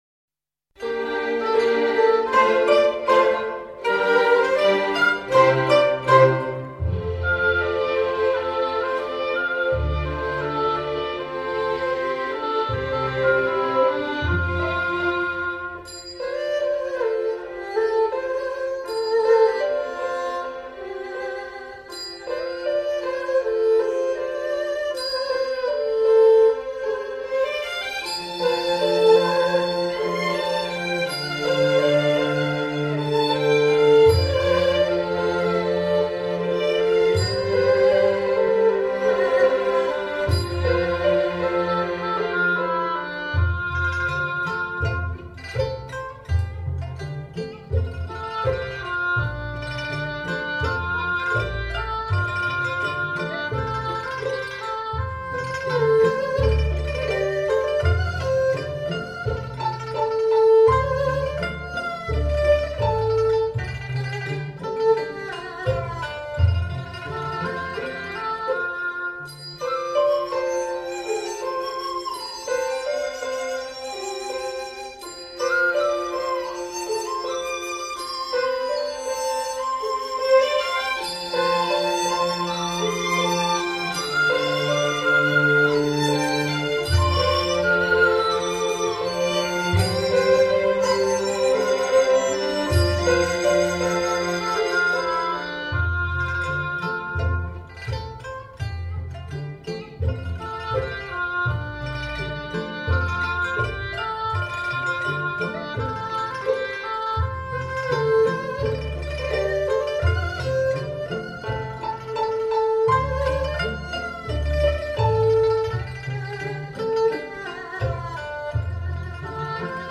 管弦轻音乐版